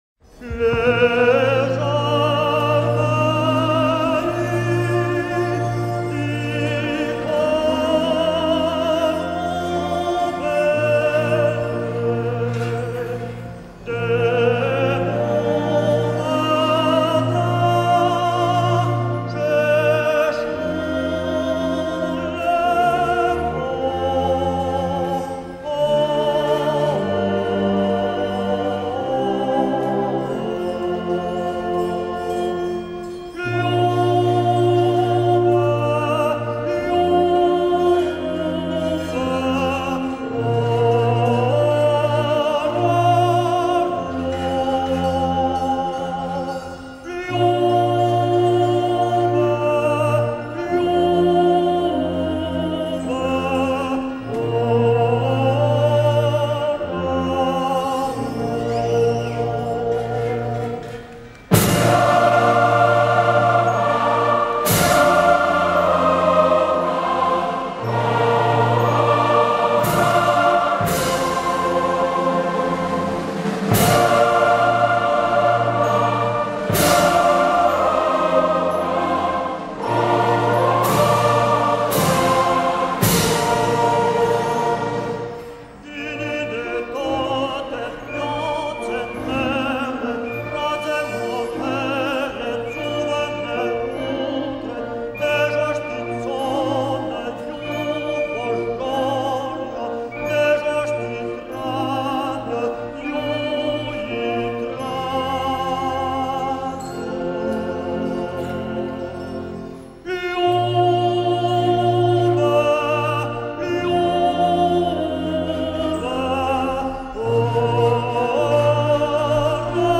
En famille, nous avons choisi d’appeler notre véhicule « Yoba » en référence à la chanson populaire Suisse du canton de fribourg « le Ranz des vaches ». Ce magnifique hymne parle des paysans qui montent leur troupeau de vaches sur les montagnes.